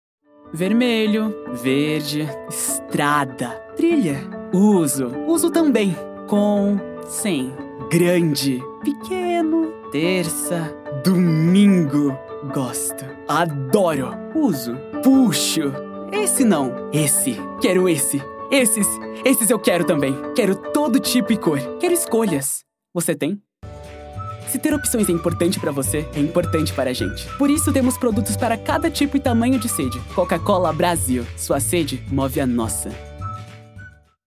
Masculino
Demo refrigerante